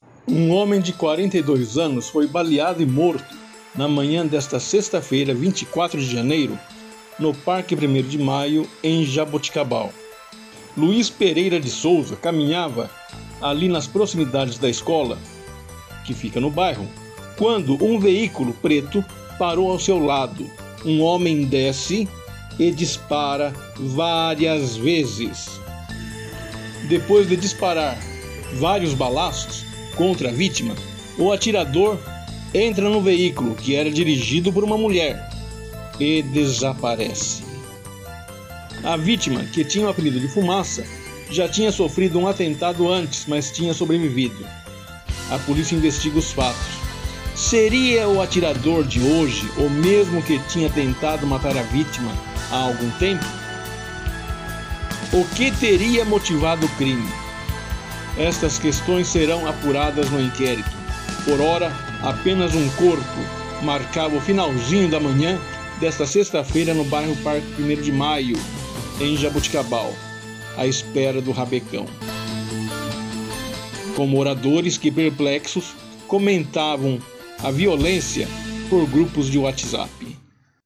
CRONICA POLICIAL